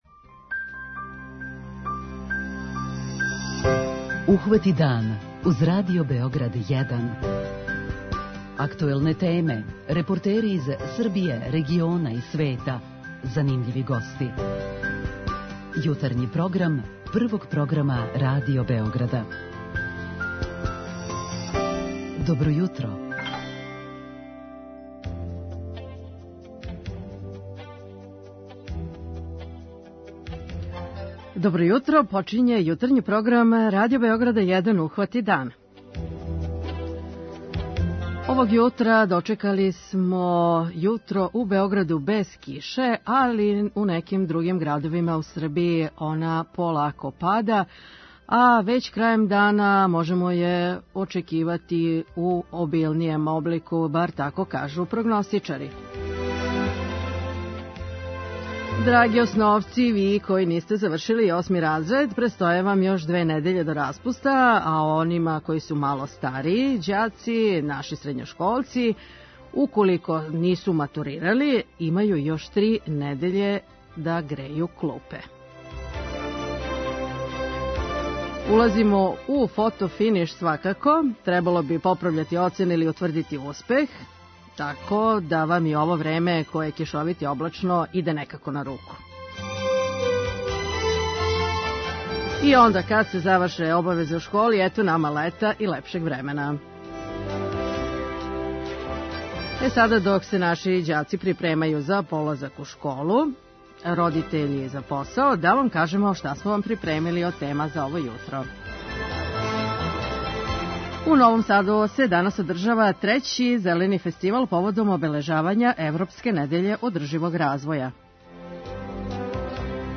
преузми : 37.78 MB Ухвати дан Autor: Група аутора Јутарњи програм Радио Београда 1!